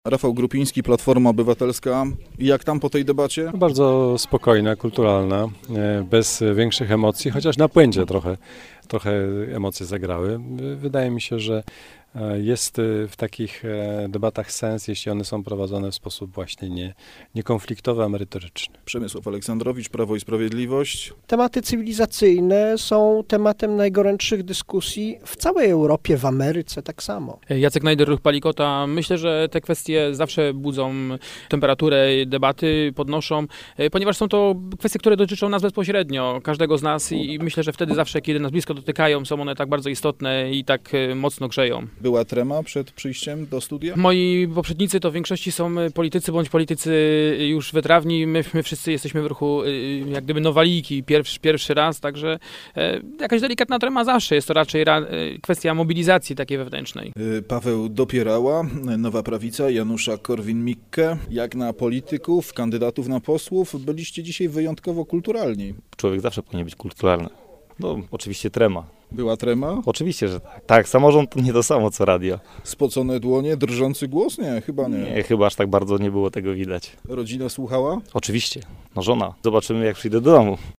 Przedwyborcza debata